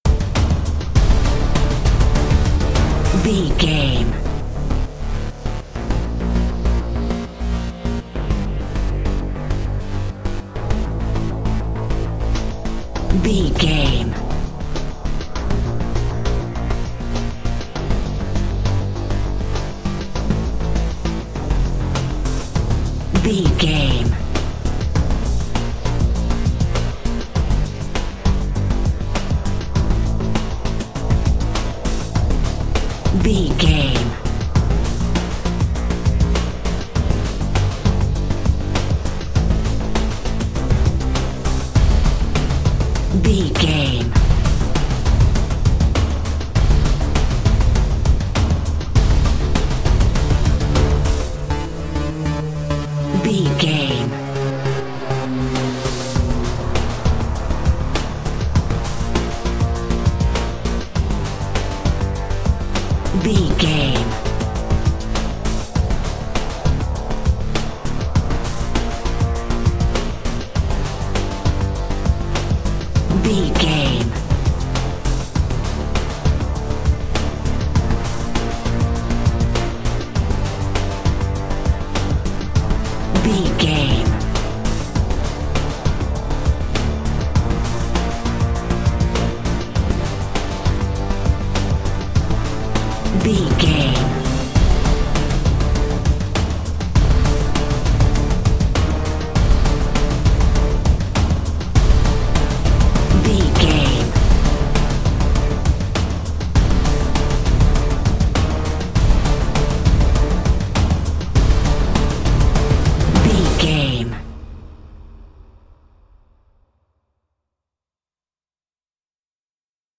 Aeolian/Minor
strings
drums
synthesiser
brass
orchestral hybrid
dubstep
aggressive
energetic
intense
synth effects
driving drum beat
epic